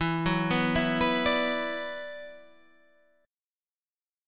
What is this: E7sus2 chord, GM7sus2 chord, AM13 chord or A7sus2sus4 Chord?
E7sus2 chord